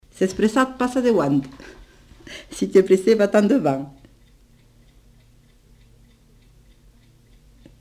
Lieu : Cathervielle
Genre : forme brève
Type de voix : voix de femme
Production du son : récité
Classification : locution populaire